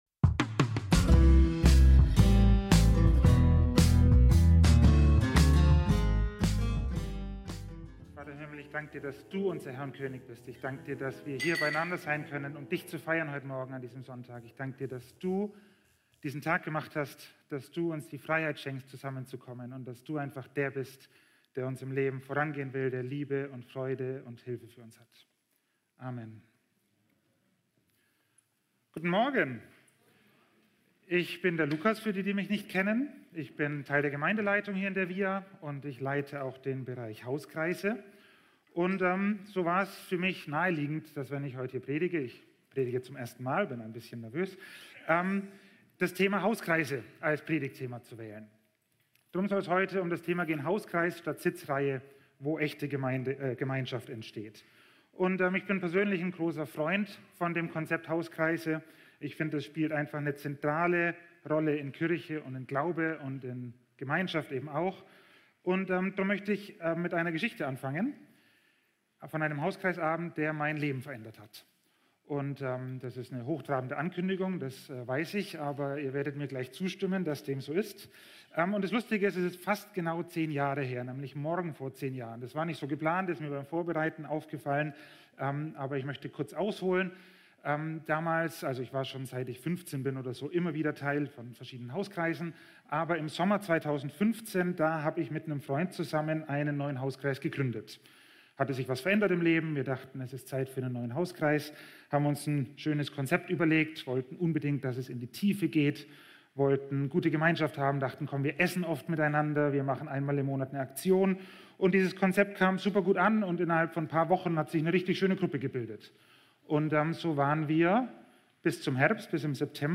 alle Predigten